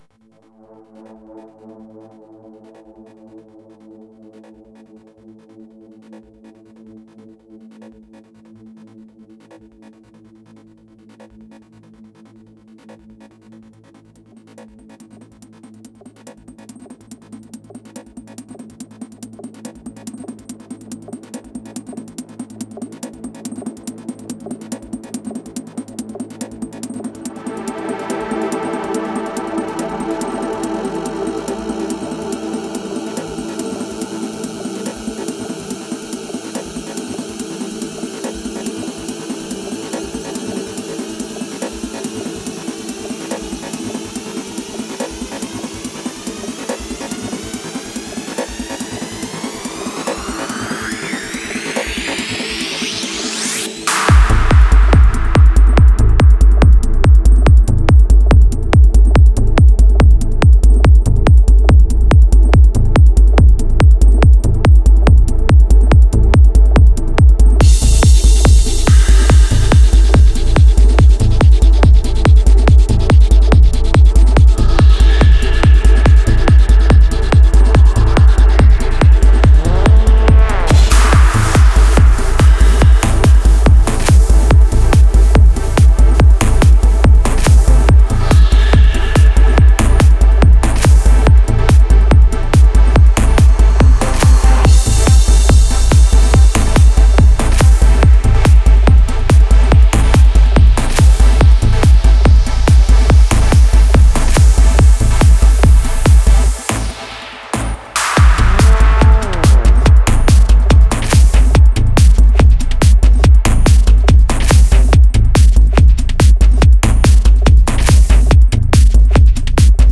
Назад в Psy-Trance
Style: Psytrance